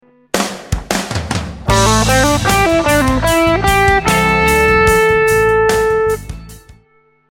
Check out the fast lick from bar 2 of the solo with the hammer ons and pull offs added. It will now sound much smoother and will also be easier to play.
Sexy Solo Hammer Ons & Pull Offs